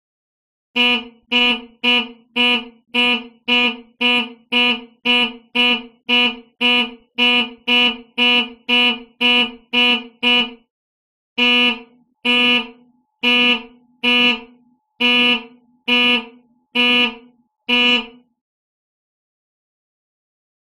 Звуки прыжка с парашютом
Вы услышите рев ветра, эмоции парашютиста, щелчки снаряжения и другие детали этого экстремального опыта.
Звуковой сигнал в самолете, оповещающий о начале прыжков